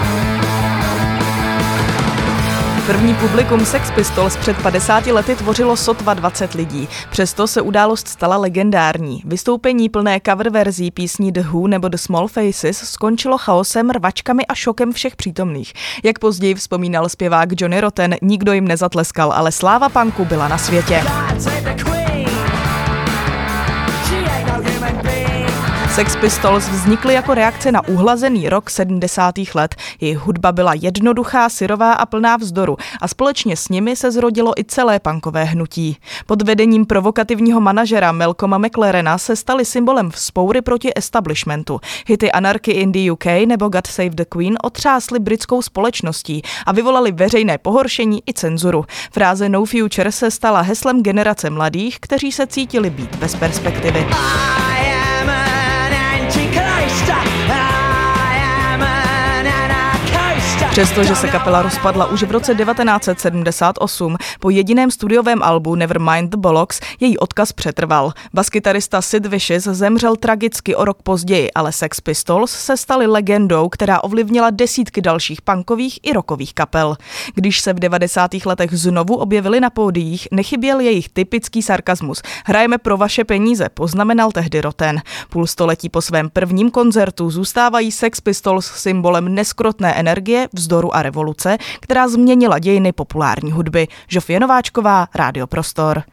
reportáži